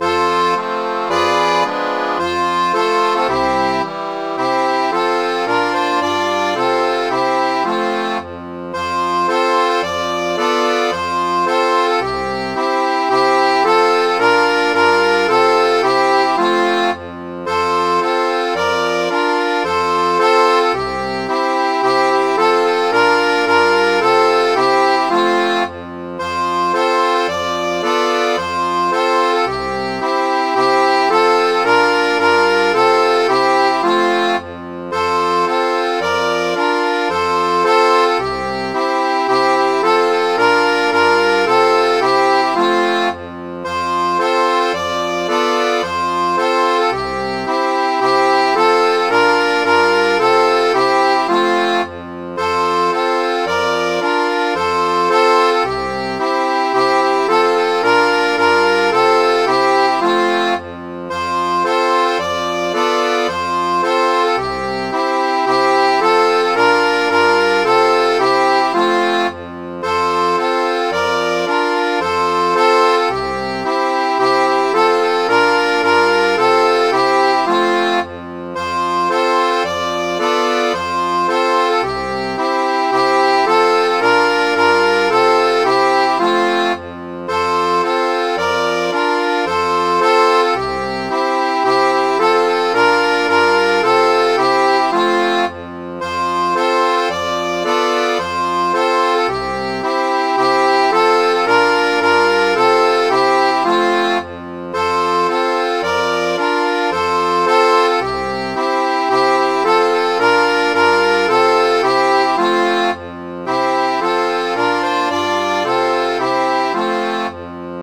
Midi File, Lyrics and Information to Fire Down Below
was a pumping shanty . When wooden ships were replaced by iron ships, the shanty was used for the capstan.
firebelow.mid.ogg